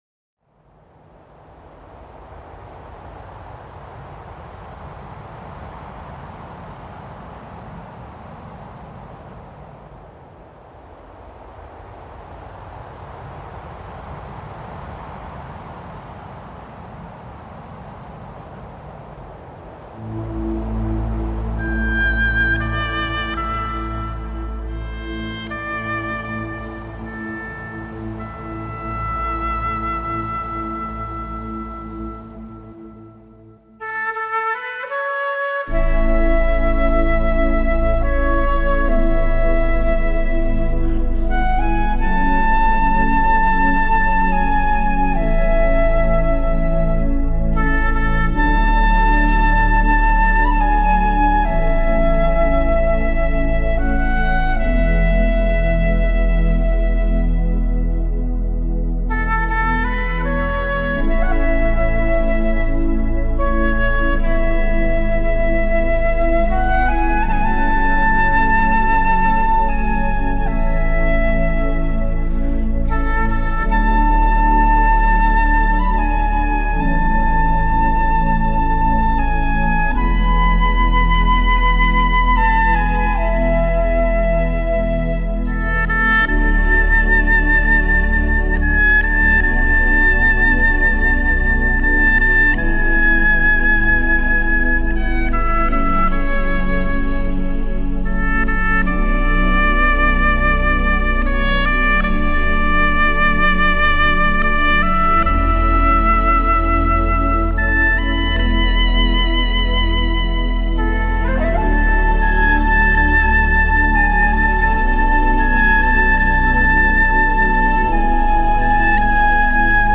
新世纪